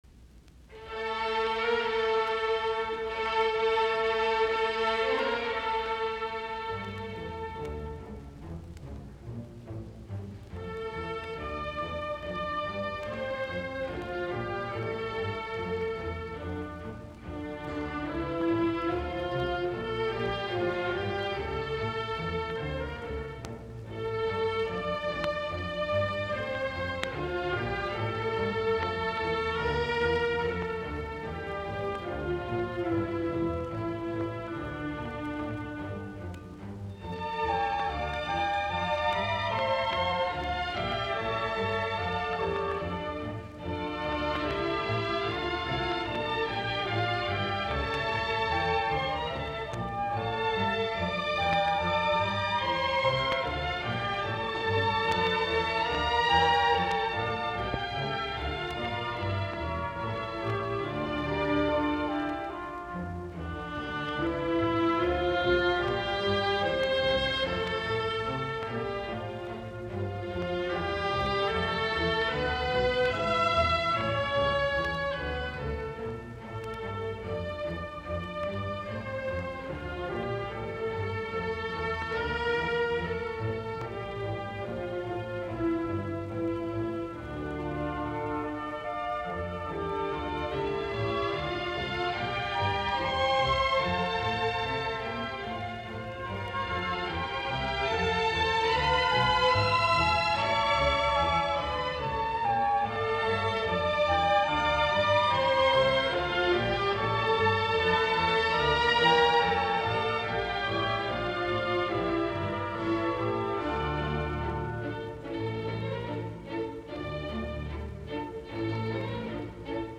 Taltioitu radioidusta esityksestä 28.2.1954.